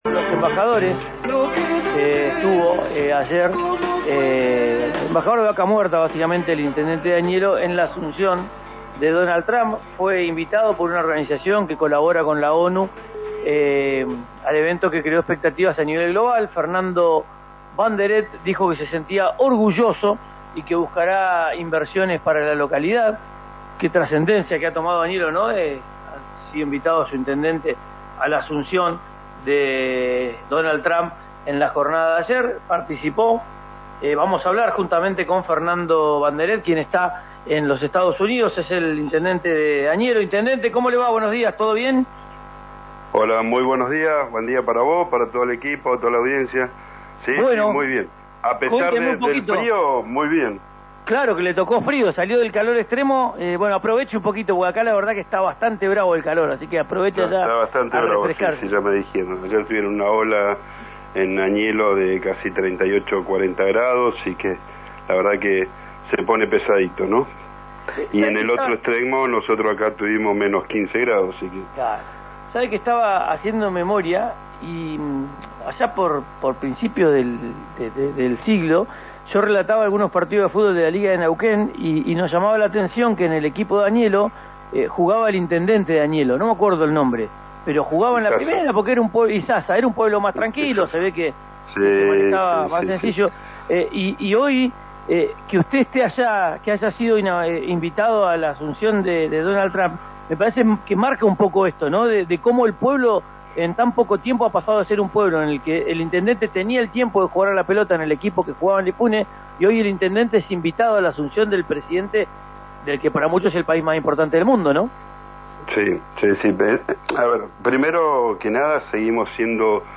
Escuchá a Fernando Banderet, intendente de Añelo, en RÍO NEGRO RADIO: